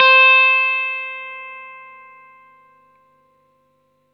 R12NOTE C +2.wav